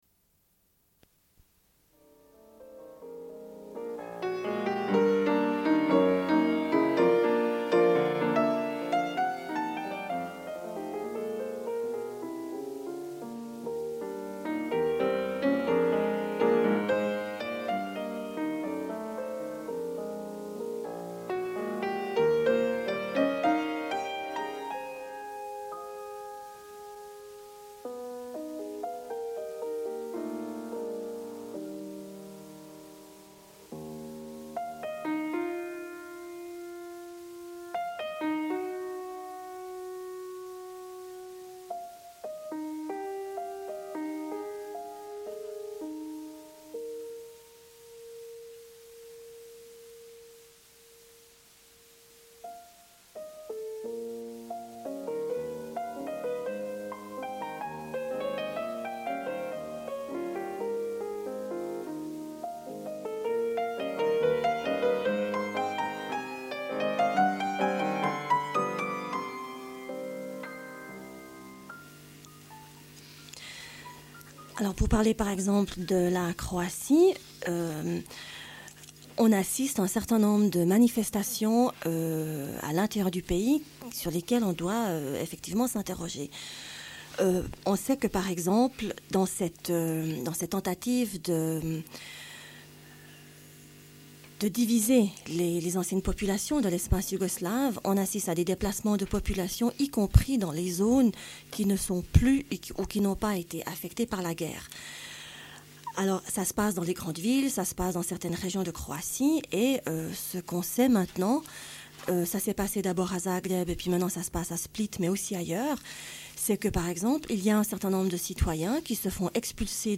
Une cassette audio, face A31:13